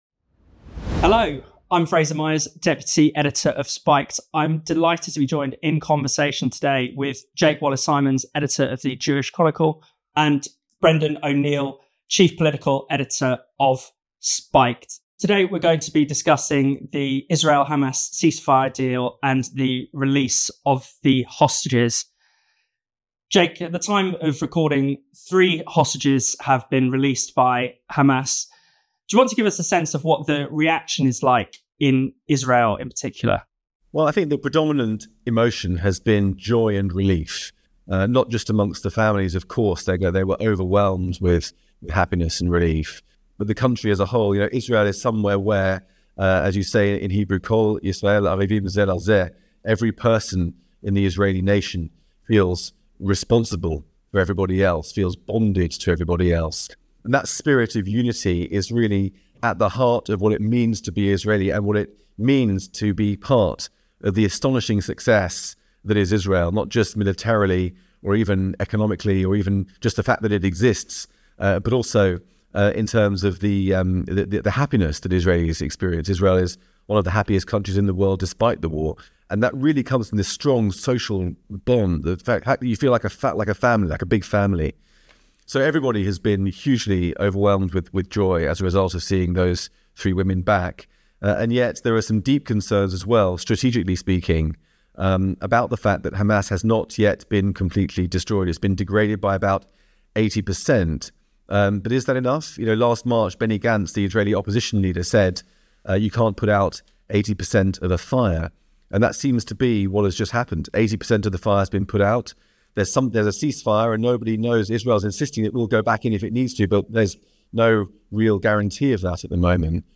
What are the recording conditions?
This is the audio from a video we have just published on our YouTube channel.